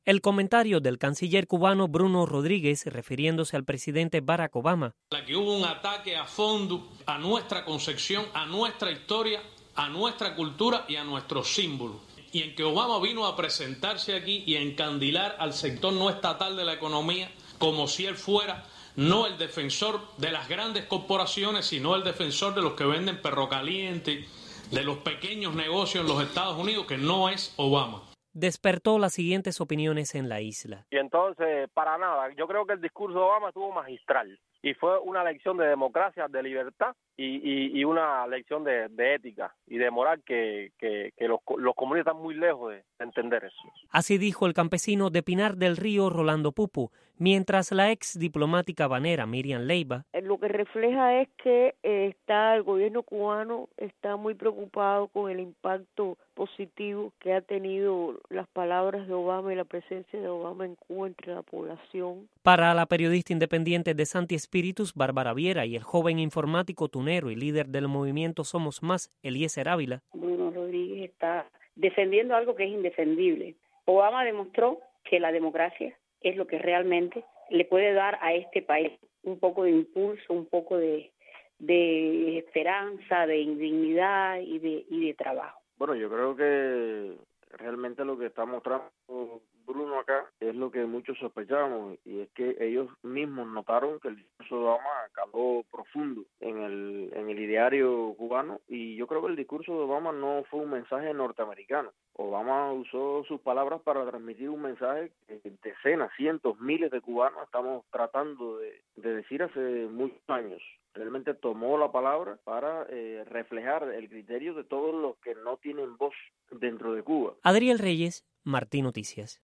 Reacciones desde Cuba a ataque de Bruno Rodríguez a Obama